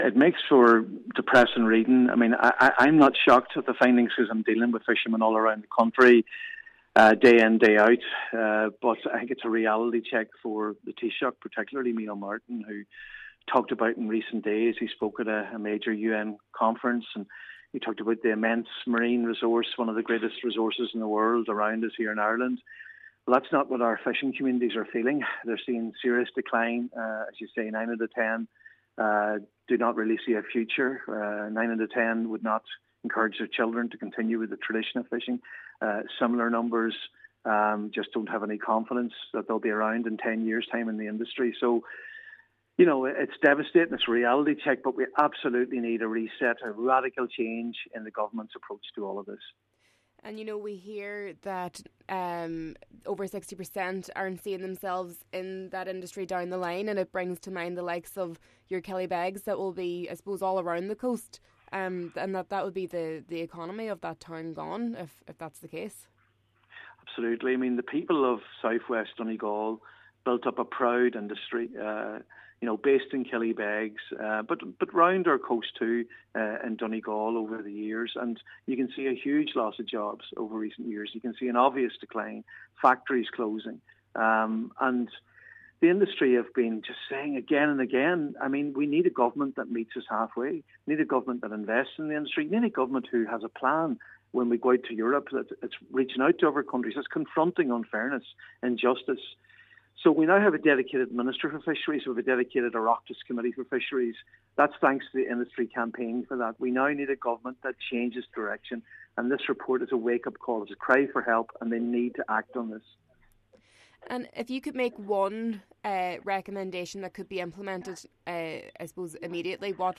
Donegal Deputy Pádraig MacLochlainn, Sinn Fein’s spokesperson on fisheries, says the Taoiseach’s statements at the conference are out of touch with the reality of people working in the industry: